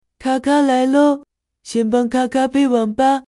首次开机后播报.MP3